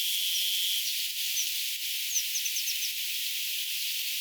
erikoinen sinitiaisen äänisarja
Onkohan tällainen ääntely keltarintasinitiaiselle tyypillistä?
erikoinen_sinitiaisen_aanisarja.mp3